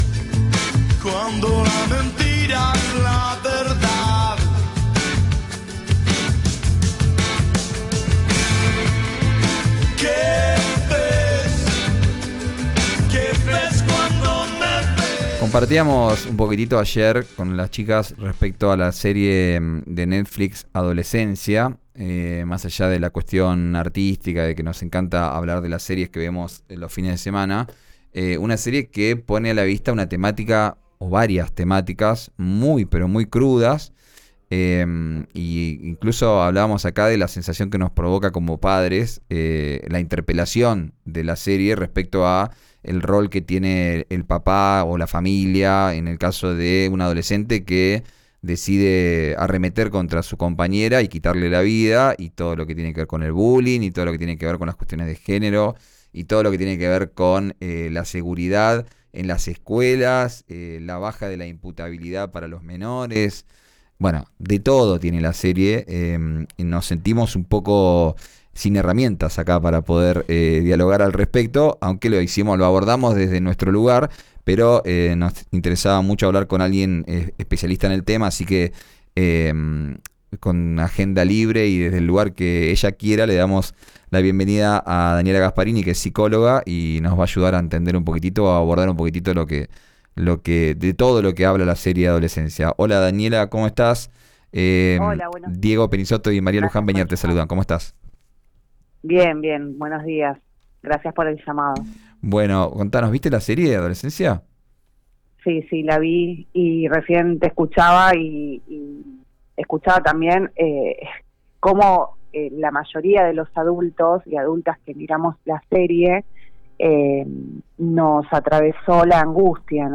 La serie »Adolescencia», que estrenó el pasado 13 de marzo en Netflix, logra exponer la vulnerabilidad de los adolescentes, los discursos de odio, la violencia de género y el uso de redes sociales. En esta nota, una profesional de la psicología opina sobre esta miniserie que es furor entre jóvenes y adultos.